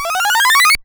その他の効果音 試聴ダウンロード ｜ seadenden 8bit freeBGM